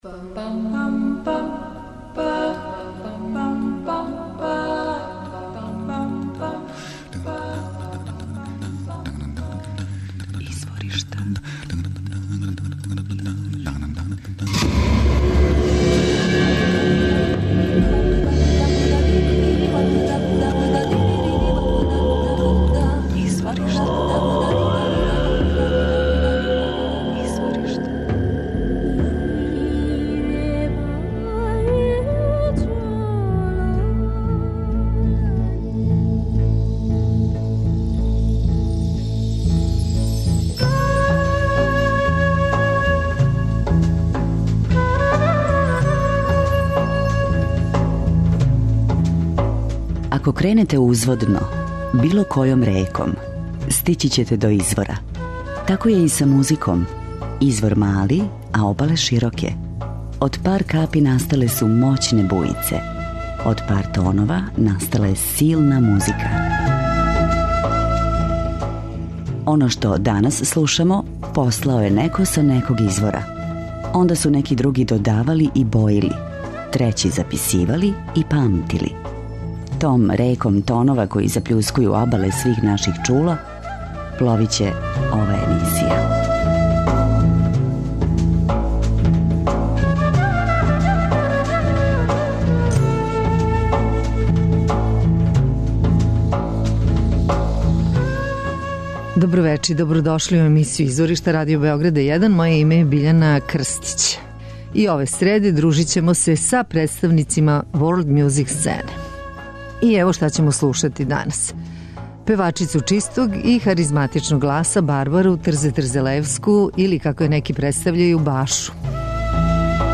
јужноафричке певачице